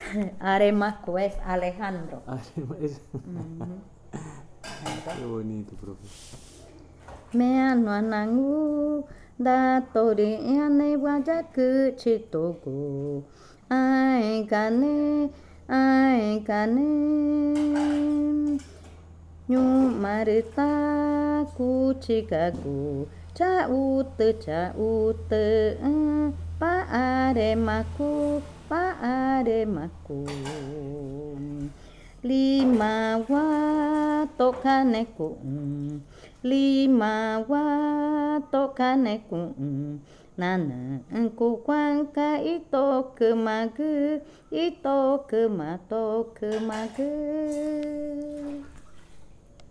Canción infantil 17.
Cushillococha